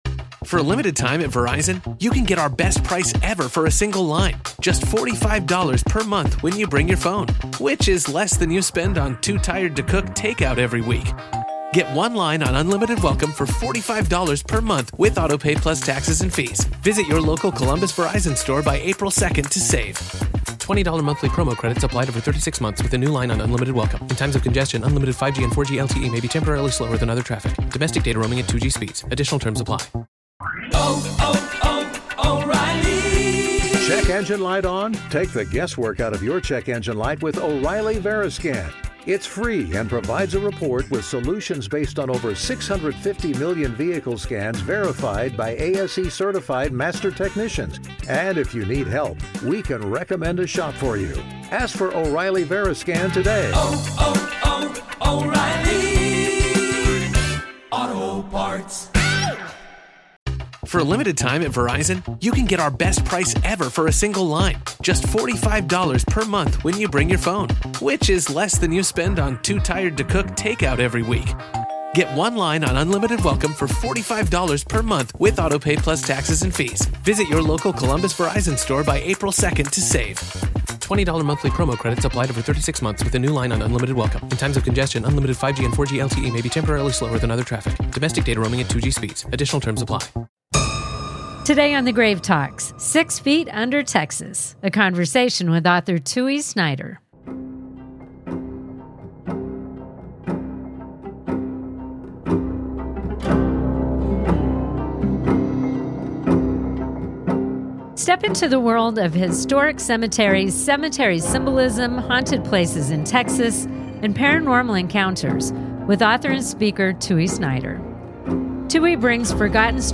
Whether you're a seasoned ghost hunter or just intrigued by the paranormal, this conversation will leave you inspired to look closer at the graves you pass by—and the stories they tell.